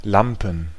Ääntäminen
Ääntäminen Tuntematon aksentti: IPA: /ˈlampən/ Haettu sana löytyi näillä lähdekielillä: saksa Käännöksiä ei löytynyt valitulle kohdekielelle. Lampen on sanan Lampe monikko.